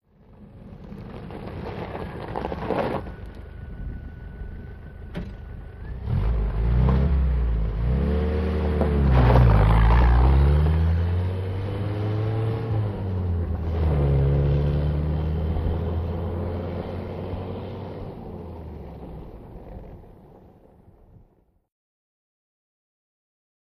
Jeep 1 | Sneak On The Lot
Jeep: In Idle, Away To Medium-distant On Gravel. Mono